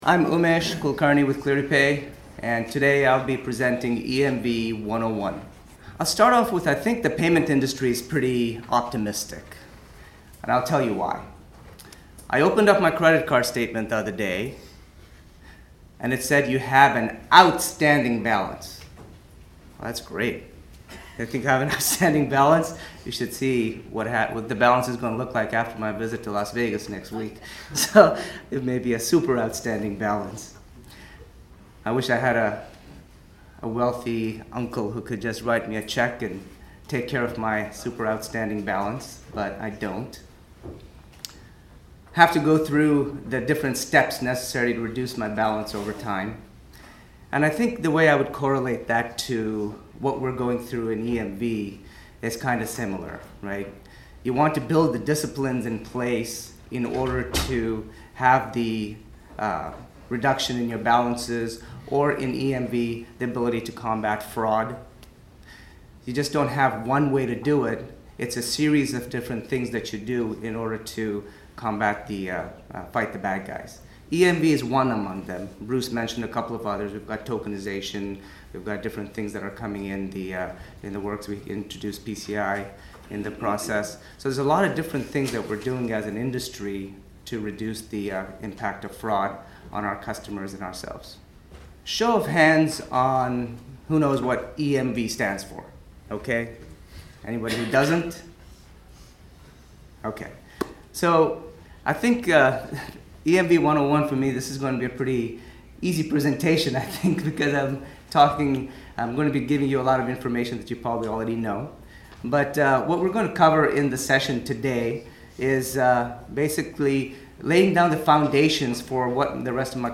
Audio recordings of the six workshop sessions are now available.